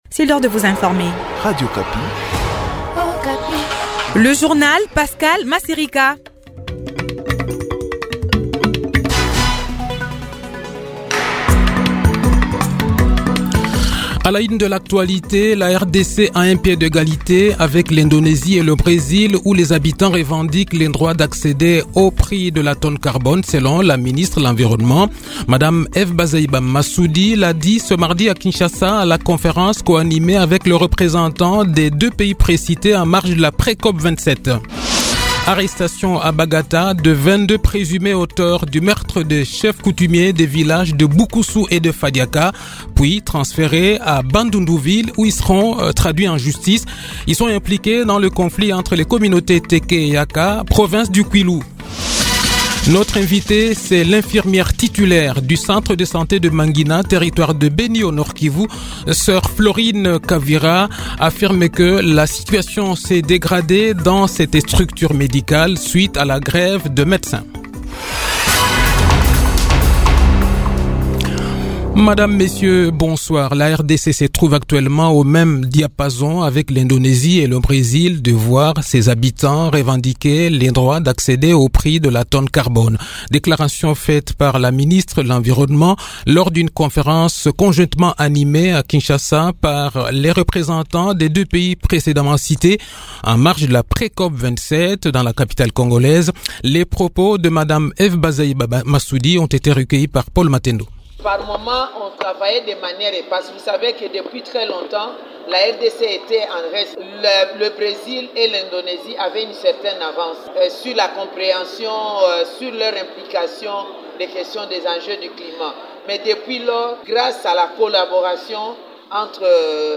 Le journal de 18 h, 4 octobre 2022